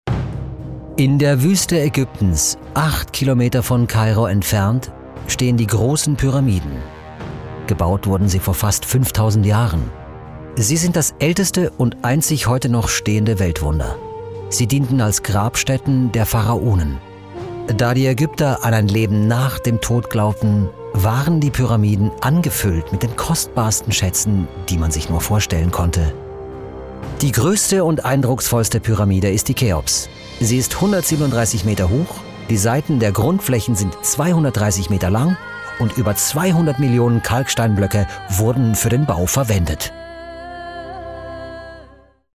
OFF-Kommentar Hochdeutsch (DE)
Schauspieler mit breitem Einsatzspektrum.